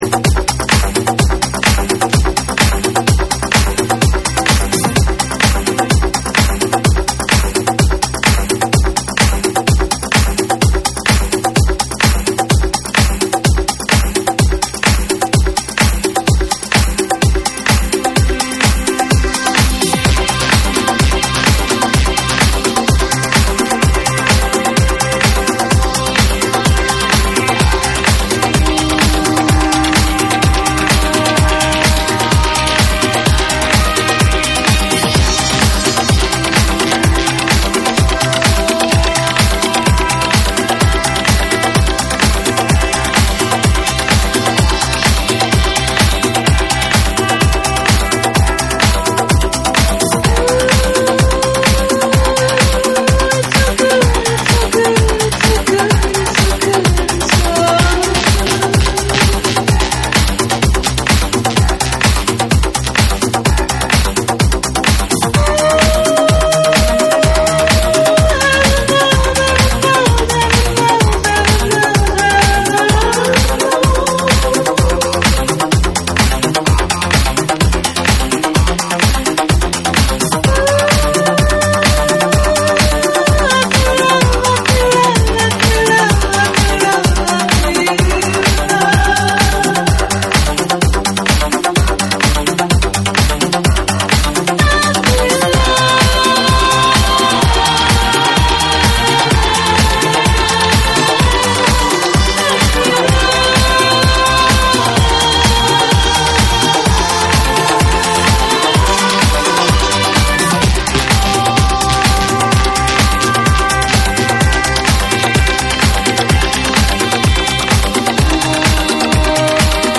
TECHNO & HOUSE / RE-EDIT / MASH UP